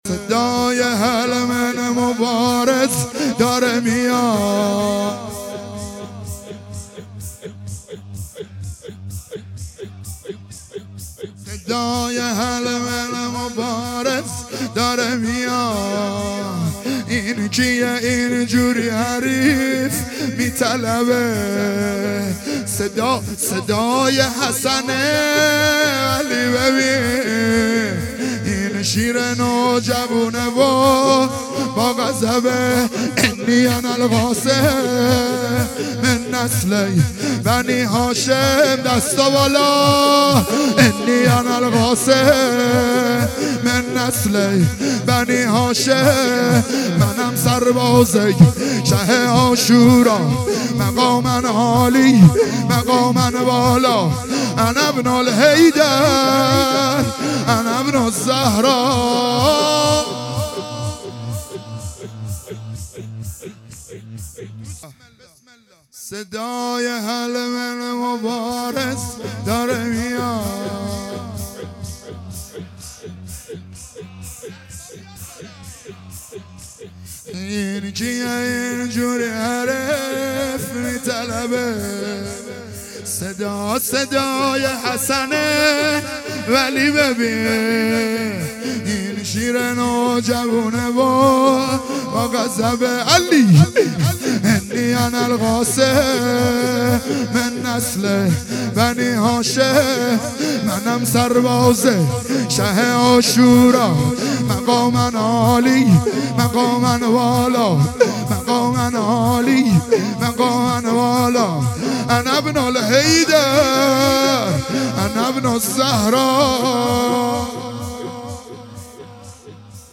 عزاداری دهه اول محرم الحرام 1442